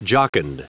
Prononciation du mot jocund en anglais (fichier audio)
Prononciation du mot : jocund